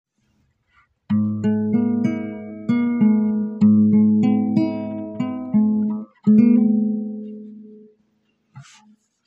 • Point-to-Point: Touching only at the vertex indicates a Dissonant interval (a 2nd or 7th.)
Dissonant Path (Point-to-Point):